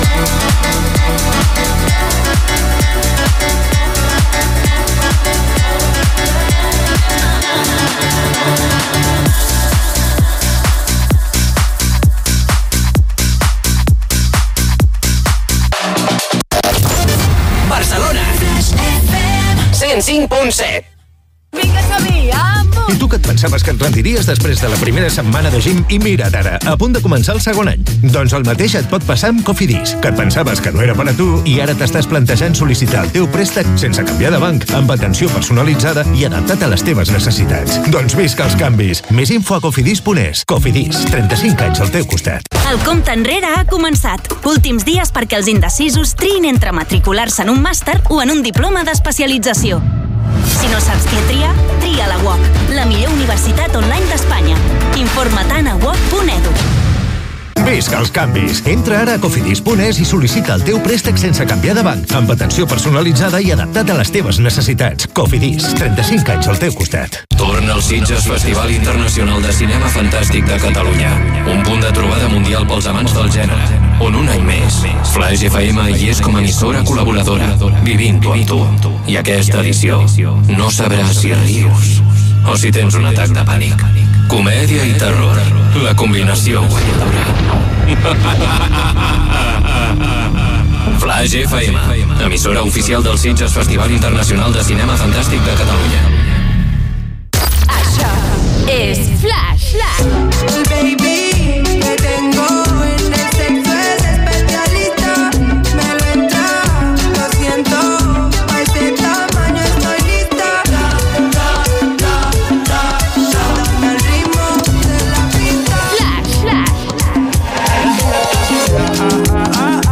Tema musical, indicatiu de l'emissora a Barcelona, publicitat, indicatiu de la ràdio, tema musical, promoció del programa "Flaix matí", tema musical, comentari del tema escoltat i presentació del següent.
Musical